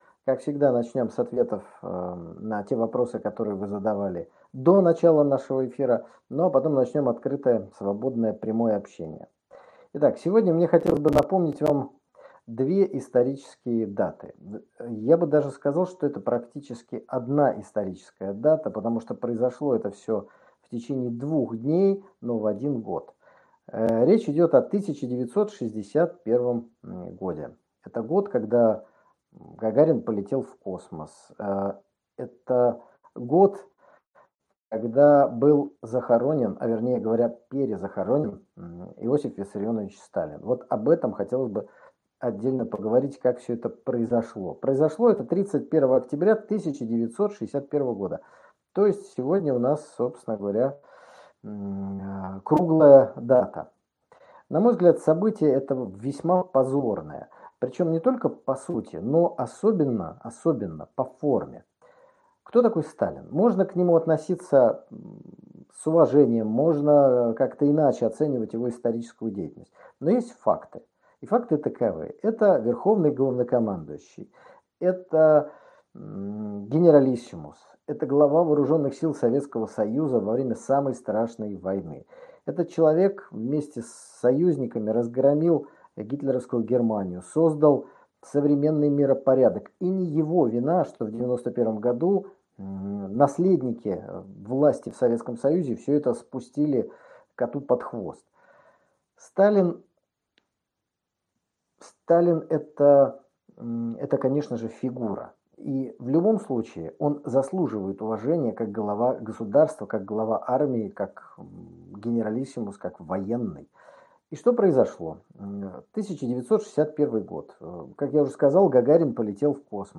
В очередном еженедельном прямом эфире было много интересных вопросов.